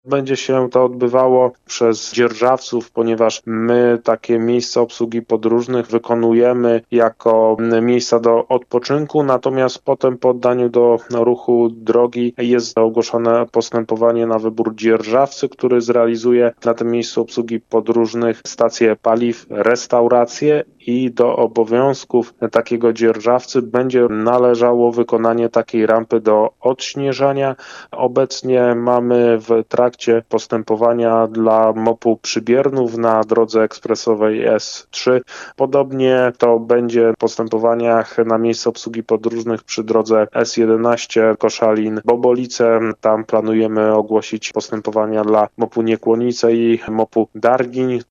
– dla Twojego radia mówi